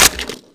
weap_sml_gndrop_1.wav